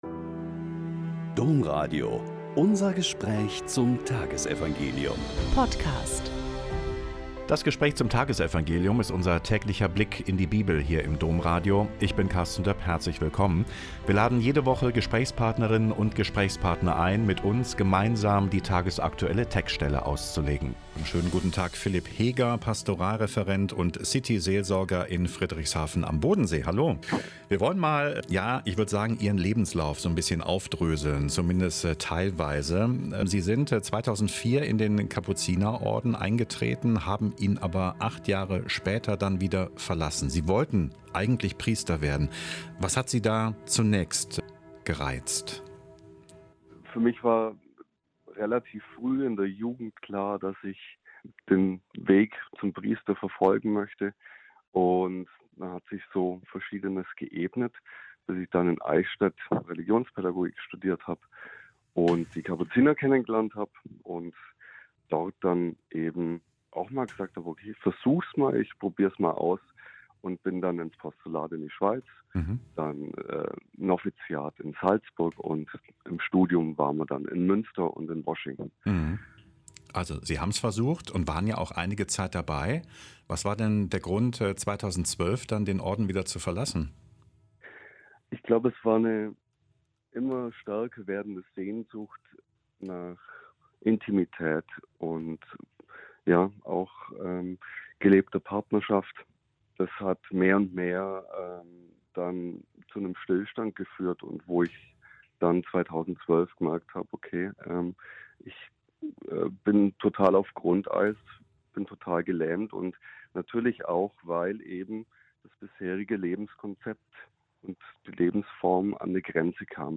Lk 7,31-35 - Gespräch